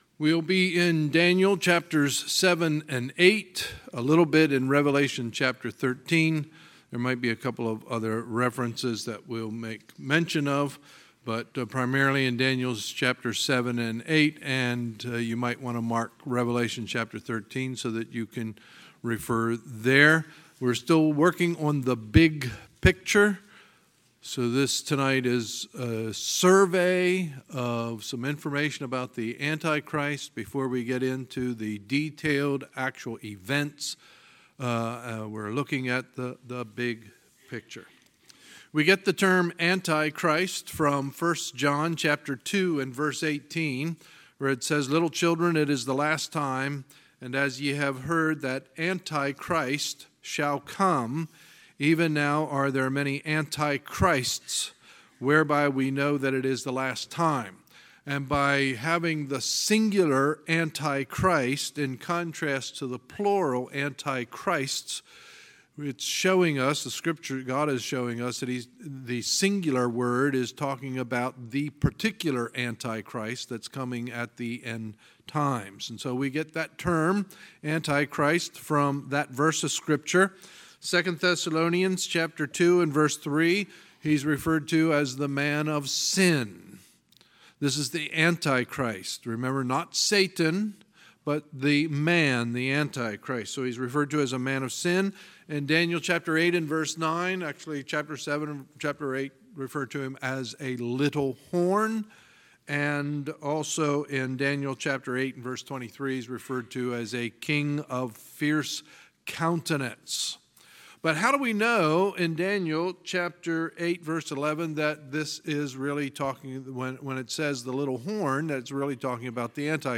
Sunday, August 19, 2018 – Sunday Evening Service